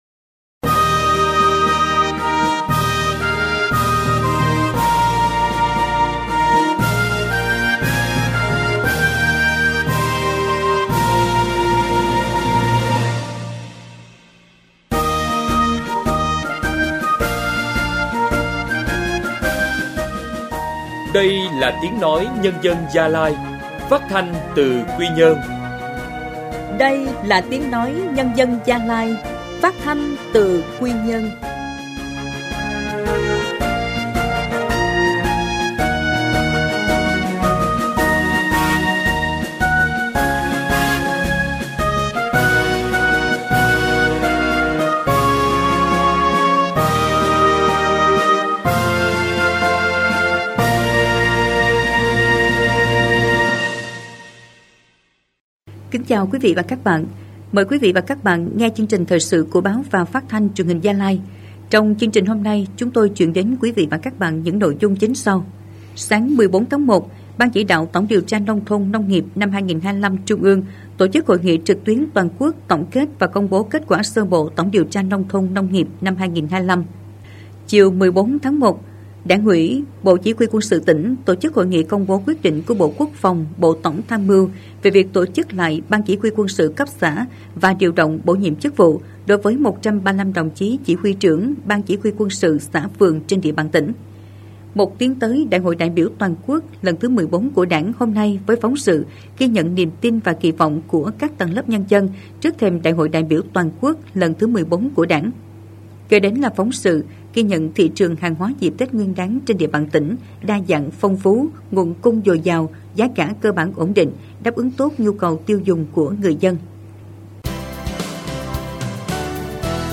Thời sự phát thanh sáng